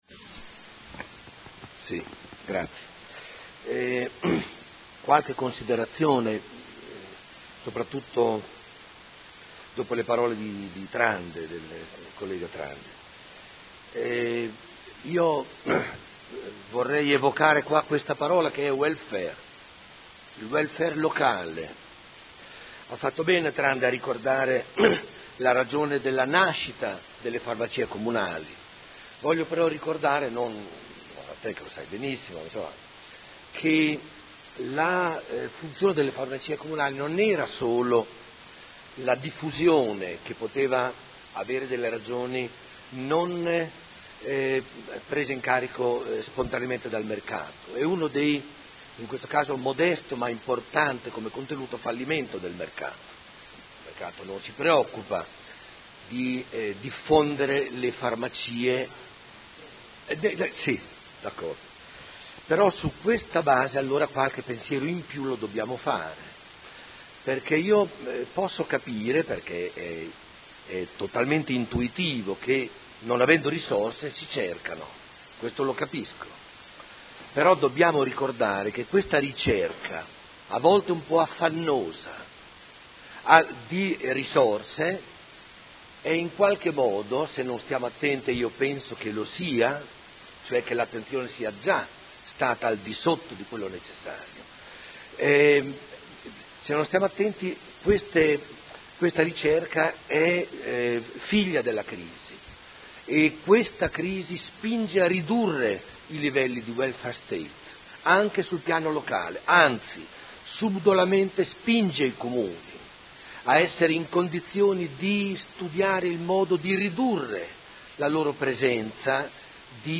Seduta del 22 ottobre. Interrogazione del Gruppo Consiliare Movimento 5 Stelle avente per oggetto: Informazioni a corredo del bando di vendita azioni Farmacie Comunali.